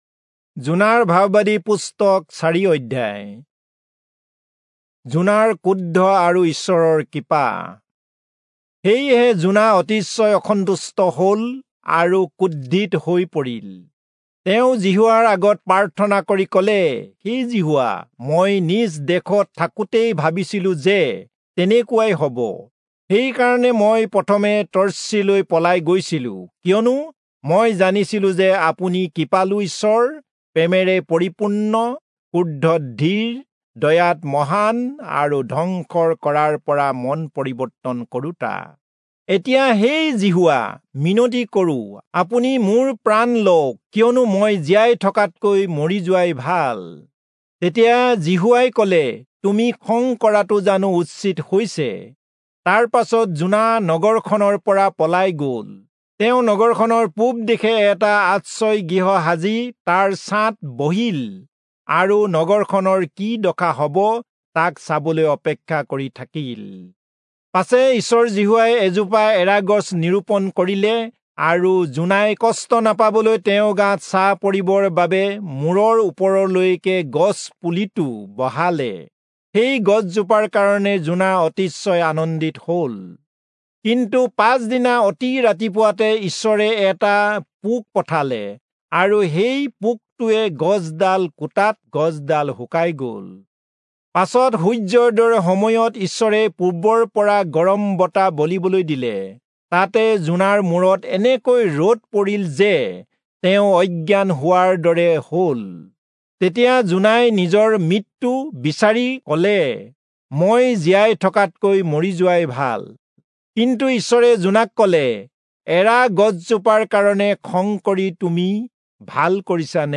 Assamese Audio Bible - Jonah 1 in Esv bible version